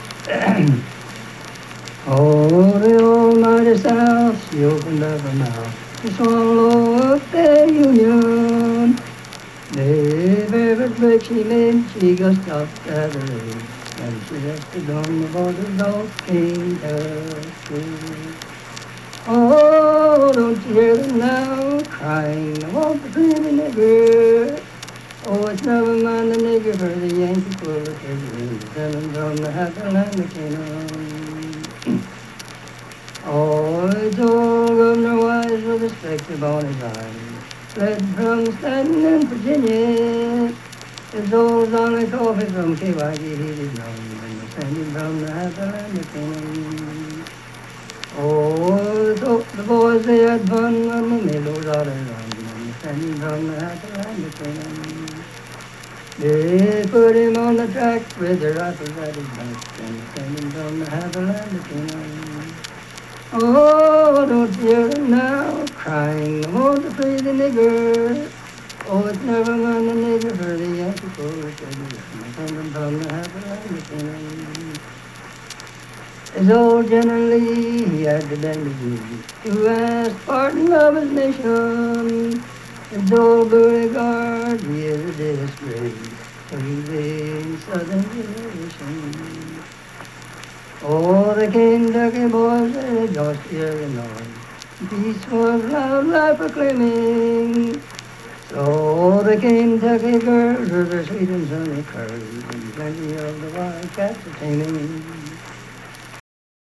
Unaccompanied vocal music
Voice (sung)
Lincoln County (W. Va.), Harts (W. Va.)